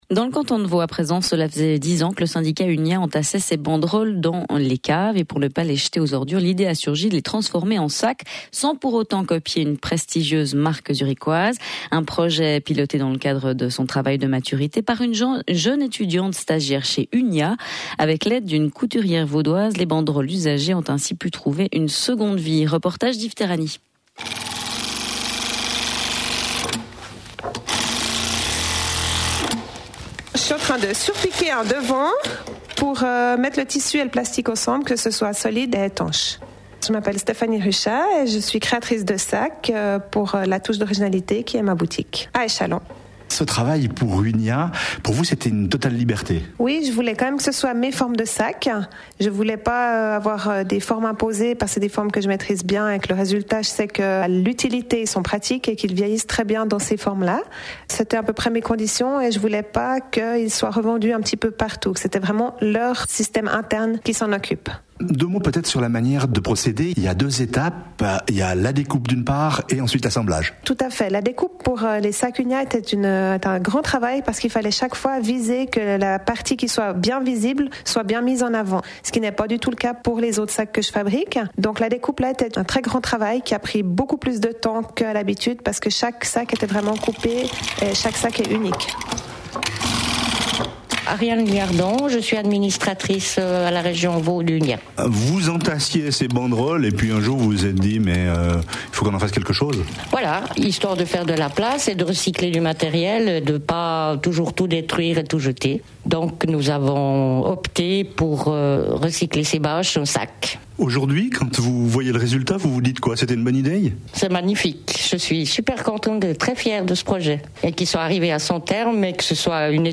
Article N° 9 Interview à la RTS